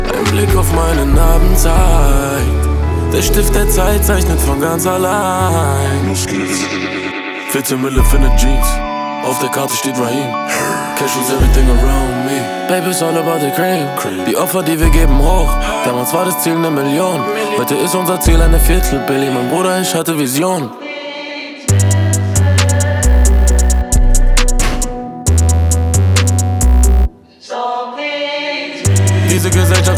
# Хип-хоп